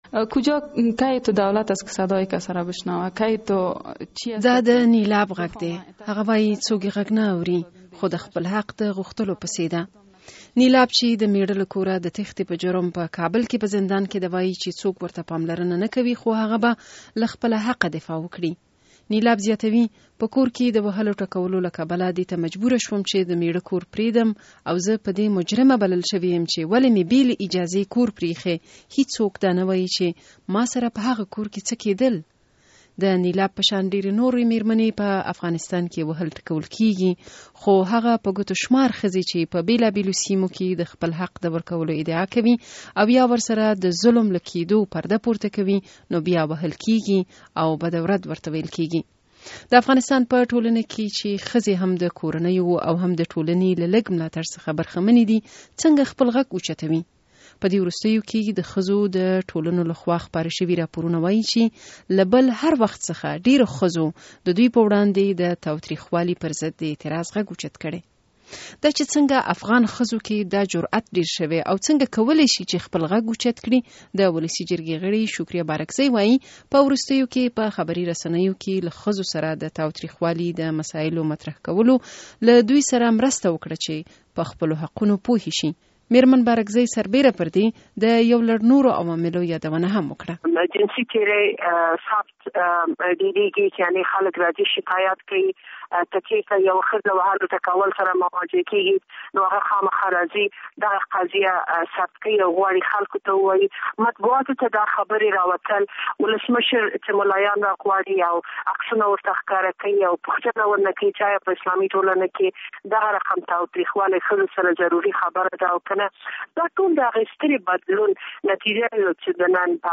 د ښځو د حقوقو په اړه مفصل راپور